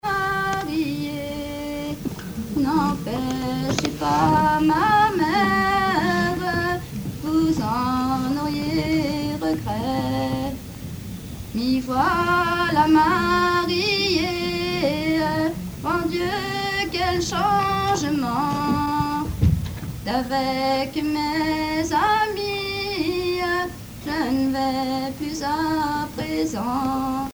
Note au folk-club Le Bourdon
Genre strophique
Pièce musicale inédite